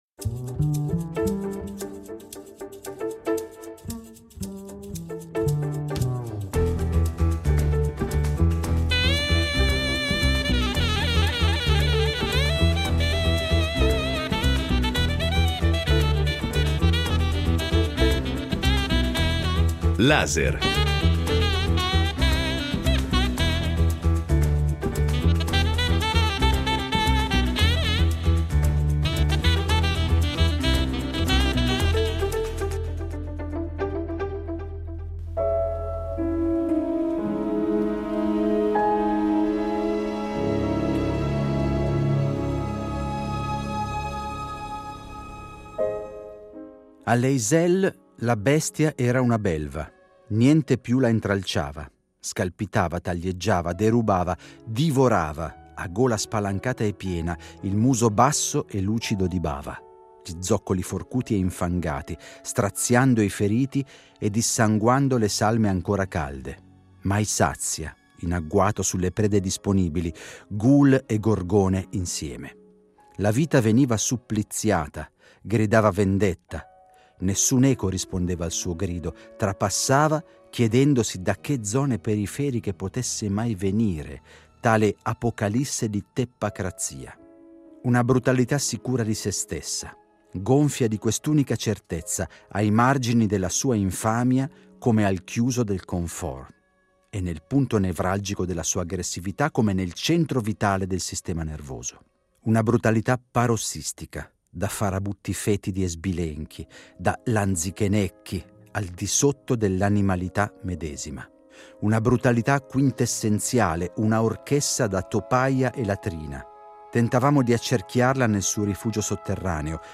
con interviste al calciatore Paolo Rossi